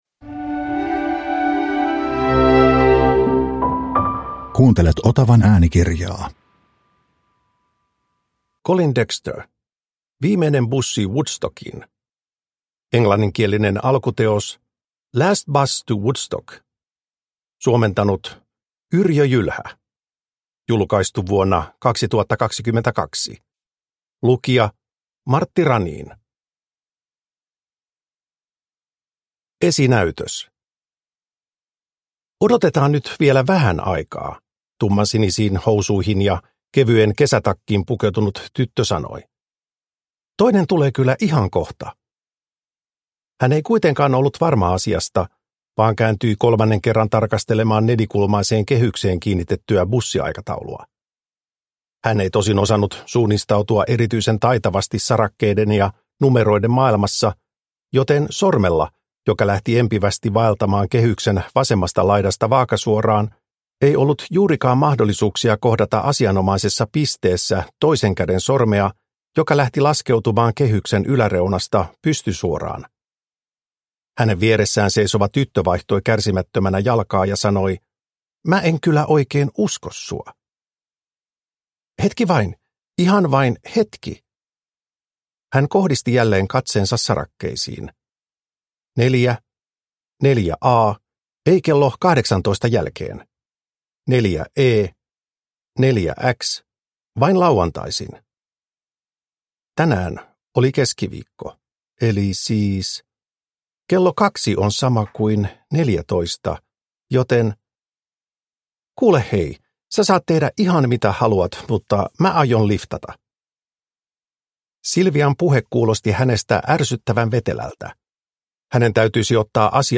Viimeinen bussi Woodstockiin – Ljudbok – Laddas ner